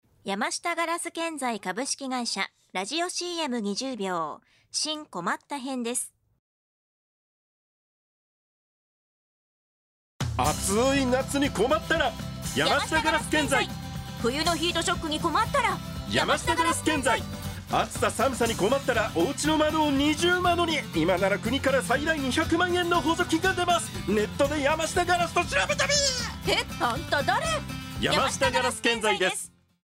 ラジオCM出稿中！
ラジオ大阪にて毎週水曜日の16:55-17:00に山下硝子建材のCMを出稿中です！